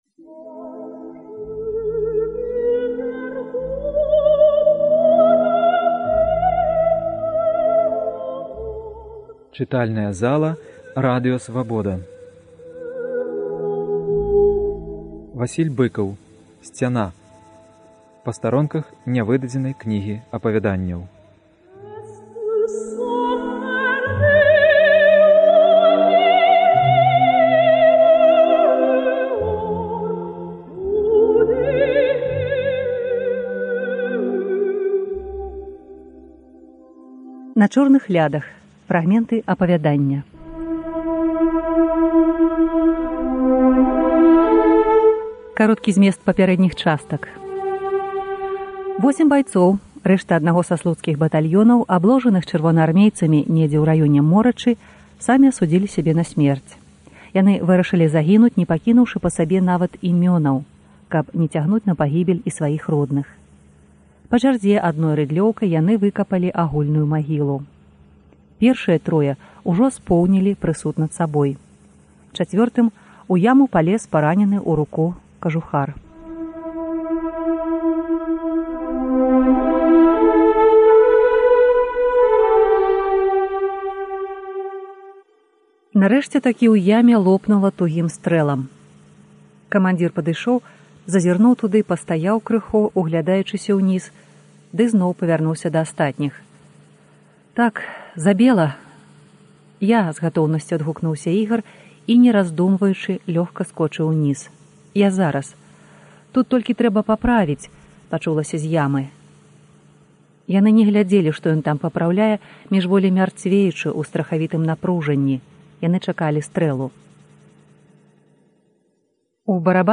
Творы мэмуарнай і мастаццкай літаратуры ў чытаньні аўтараў або журналістаў Свабоды. У перадачах бяруць удзел аўтары, героі твораў або тыя, хто іх ведаў.